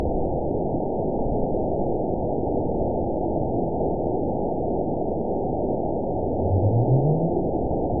event 919782 date 01/23/24 time 13:30:08 GMT (1 year, 3 months ago) score 9.67 location TSS-AB05 detected by nrw target species NRW annotations +NRW Spectrogram: Frequency (kHz) vs. Time (s) audio not available .wav